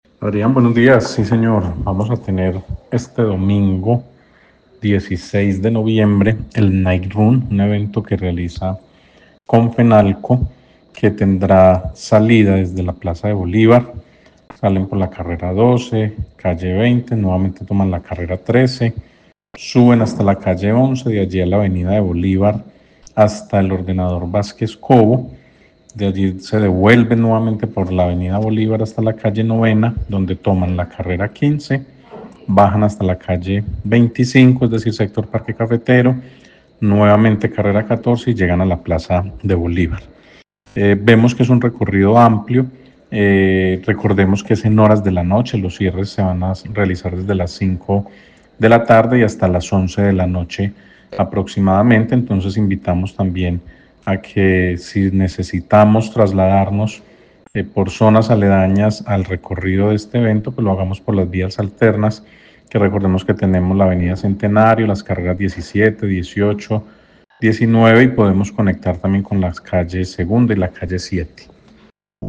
Daniel Jaime Castaño, secretario tránsito, Armenia